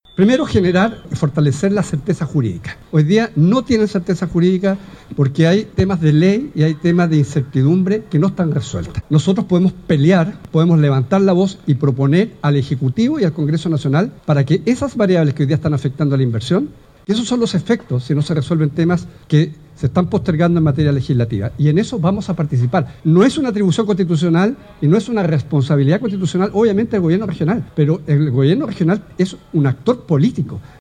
Después, en uno de los jardines de la Universidad San Sebastián, el gobernador Alejandro Santana respondió preguntas asociadas a su administración, lamentando la reducción de presupuesto para este año, y por la cual anunció una visita a la Dirección de Presupuesto para la próxima semana.